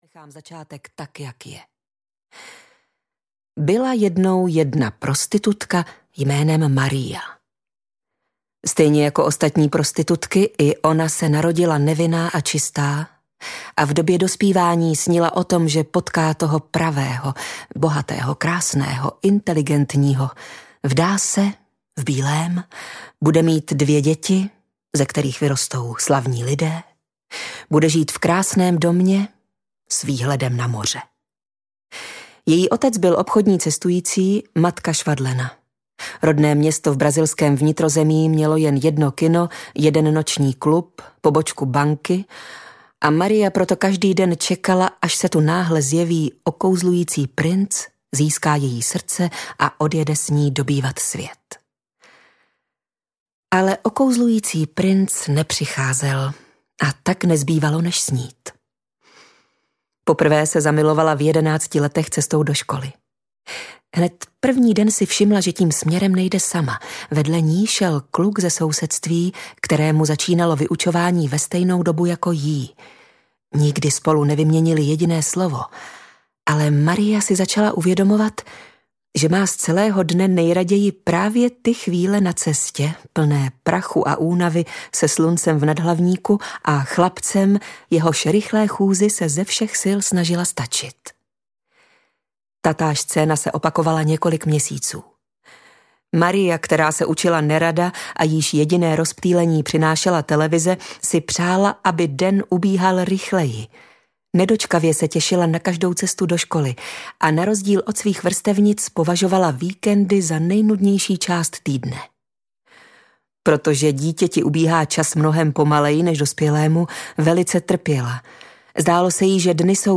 Jedenáct minut audiokniha
Ukázka z knihy